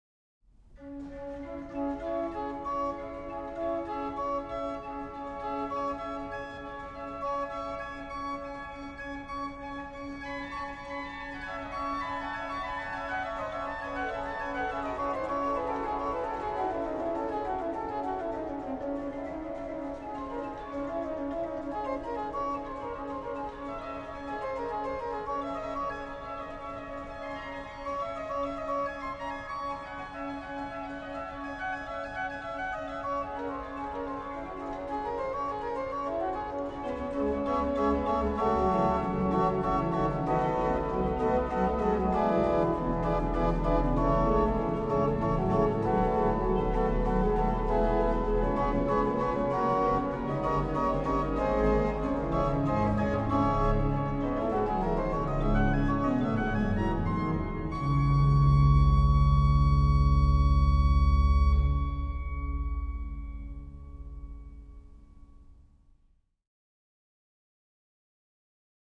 rh: OW: Oct4
lh: BW: Pr4
Ped: Oct8
m. 21, lh: HW: Pr8, Oct4; Ped: +32Unt